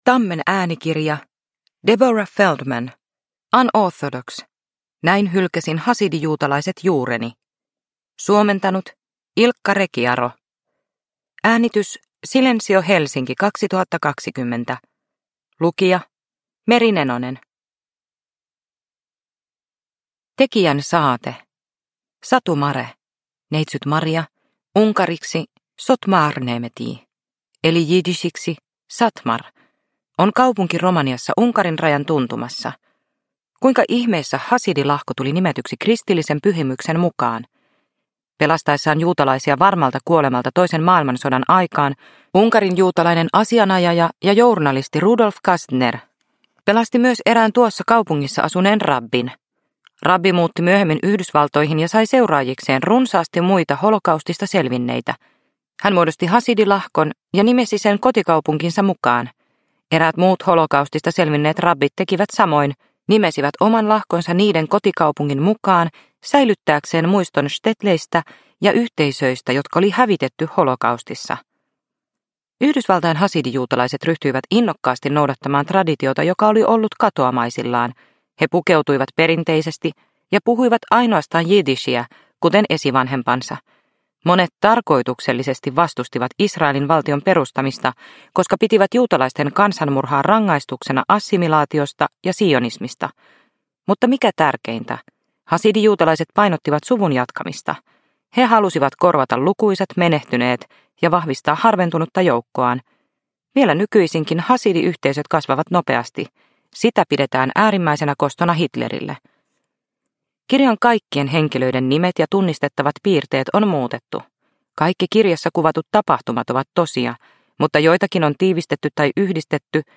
Unorthodox - Näin hylkäsin hasidijuutalaiset juureni – Ljudbok – Laddas ner